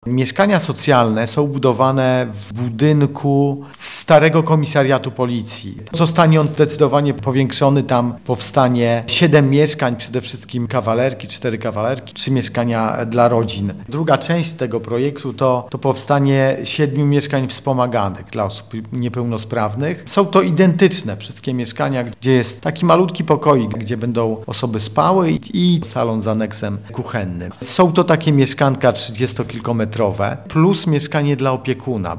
Powstanie siedem mieszkań w budynku starego komisariatu policji – mówi wójt gminy Wisznice Piotr Dragan.